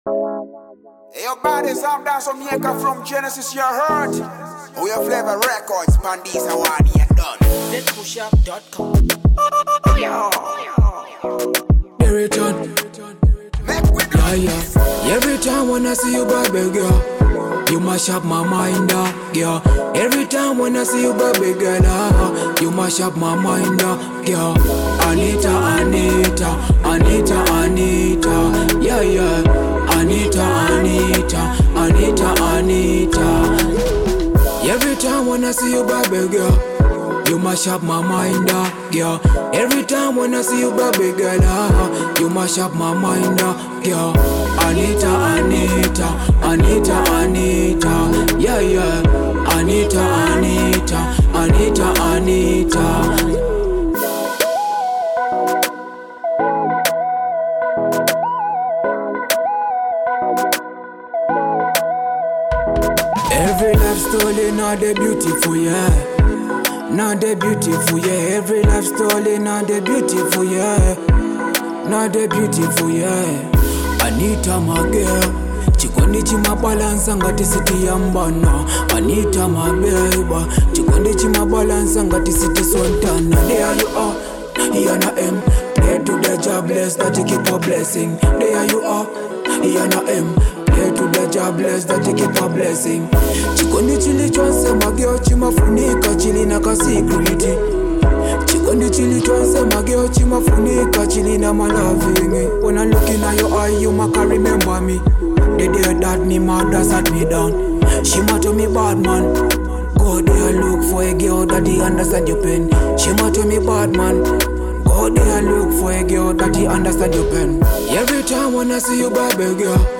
a clear love joint